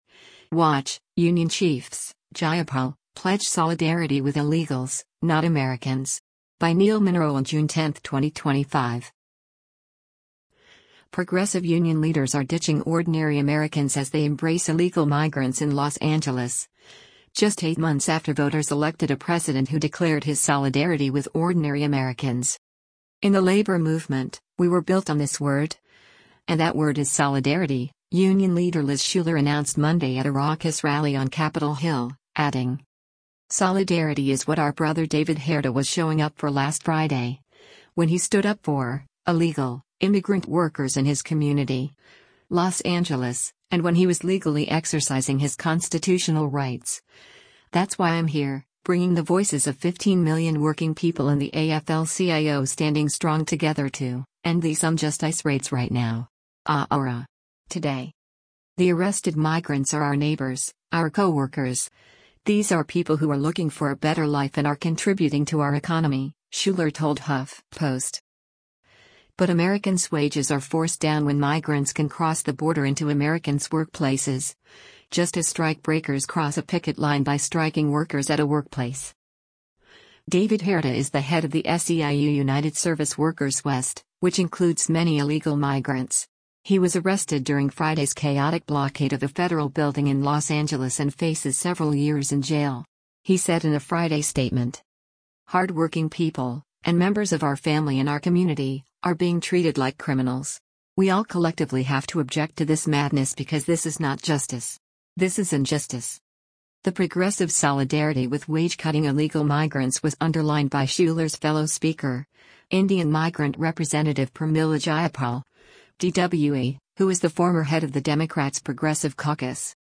“In the labor movement, we were built on this word, and that word is ‘Solidarity,'” union leader Liz Shuler announced Monday at a raucous rally on Capitol Hill, adding:
The demonstration at the Capitol included a speech from a Spanish-language, illegal migrant who said through a translator that she has been in the United States for 40 years.